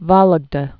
(vôləg-də)